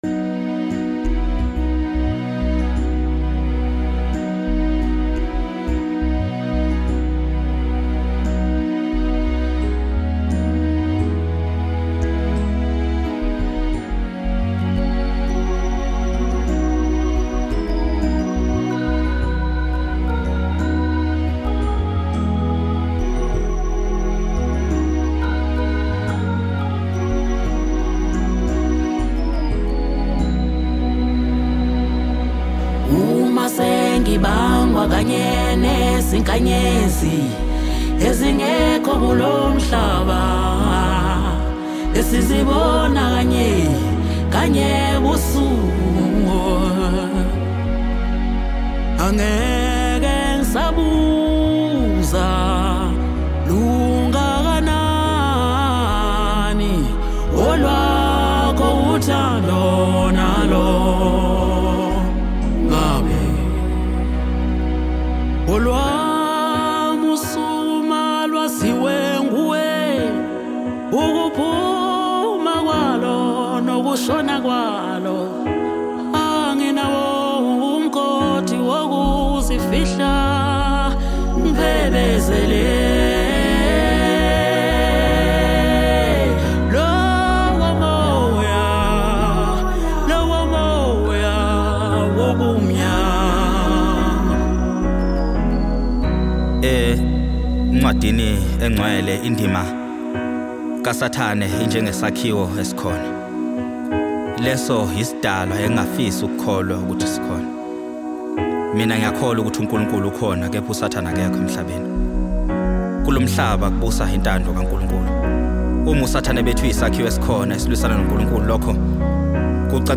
Home » Amapiano » DJ Mix